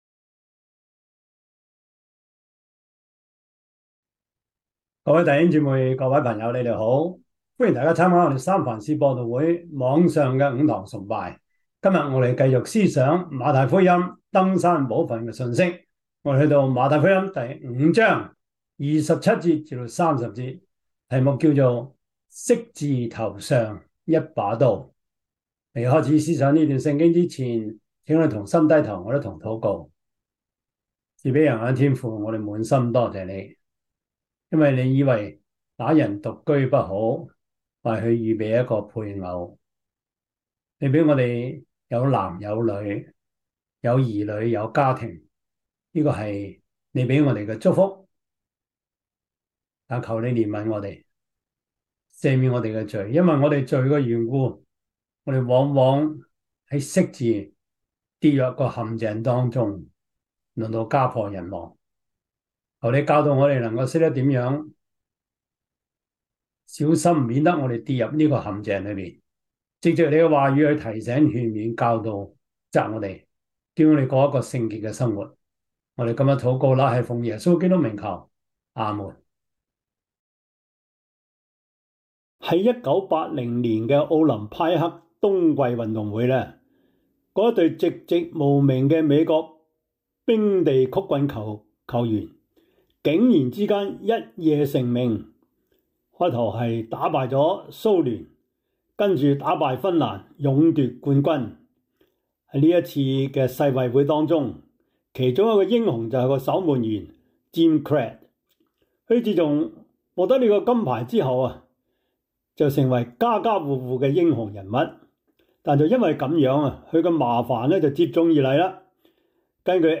馬太福音 5:27-30 Service Type: 主日崇拜 馬太福音 5:27-30 Chinese Union Version
Topics: 主日證道 « 禱告 – 與敵奮勇爭戰的生活 團契生活 »